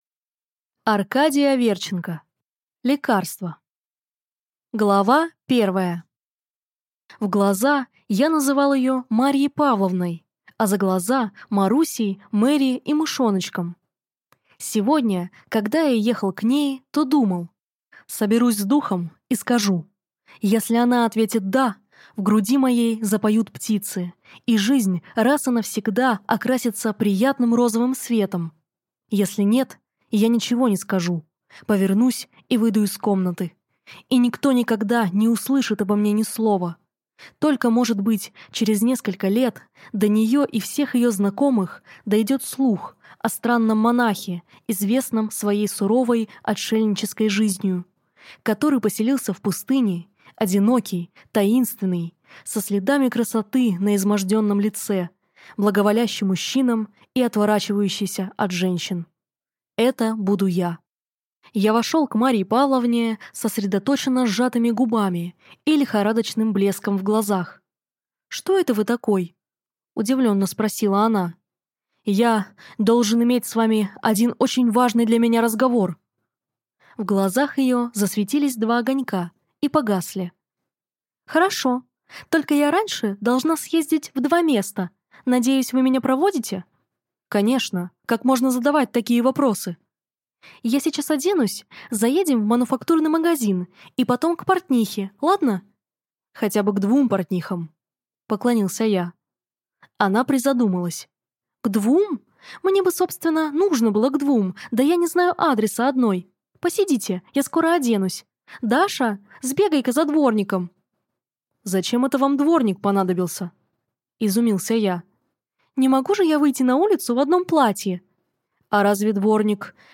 Аудиокнига Лекарство | Библиотека аудиокниг